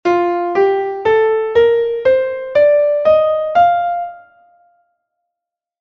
Escala Maior
4º tipo ou Mixolidia